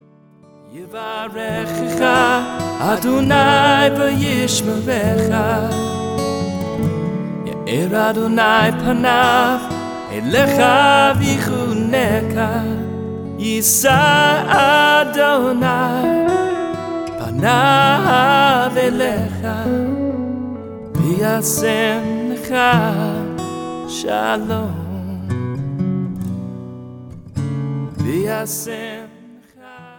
CJM (Contemporary Jewish Music)